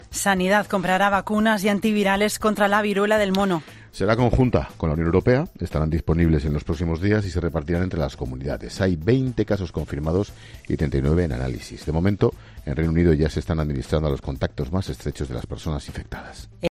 Así lo ha anunciado la ministra de Sanidad en rueda de prensa